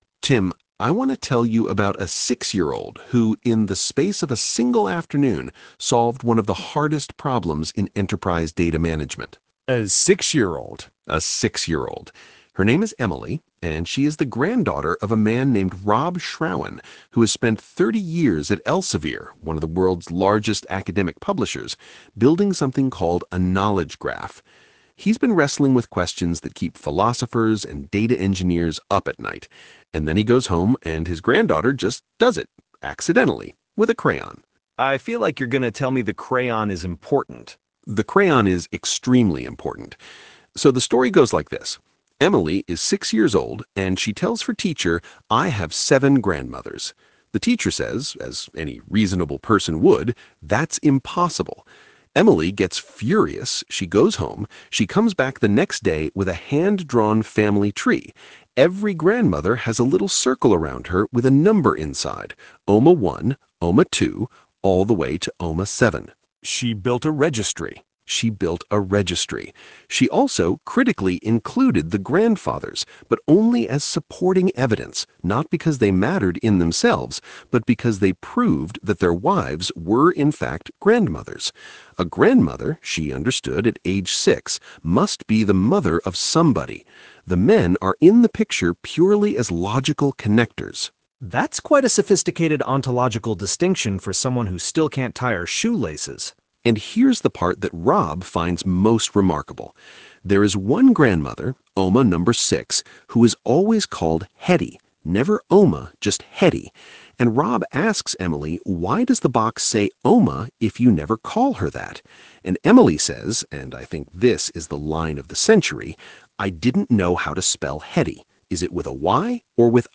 A discussion-style summary of the talk, captured in podcast.opus.